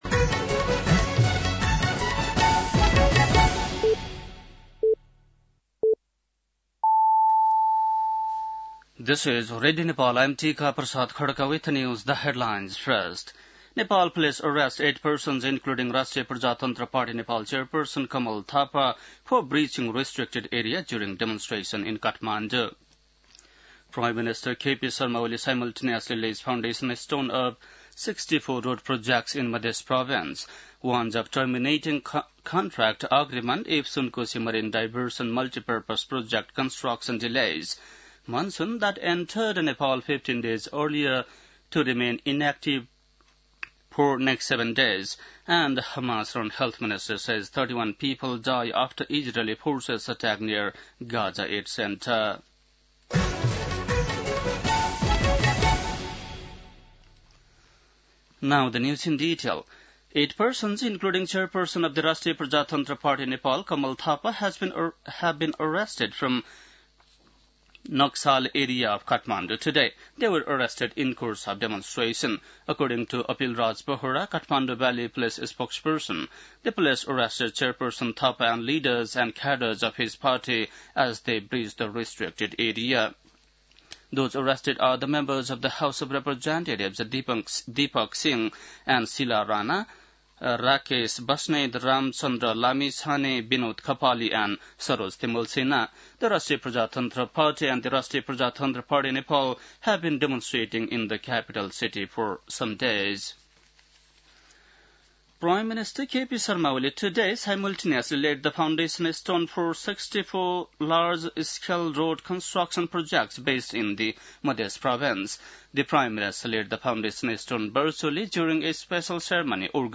बेलुकी ८ बजेको अङ्ग्रेजी समाचार : १८ जेठ , २०८२
8-pm-english-news-2-18.mp3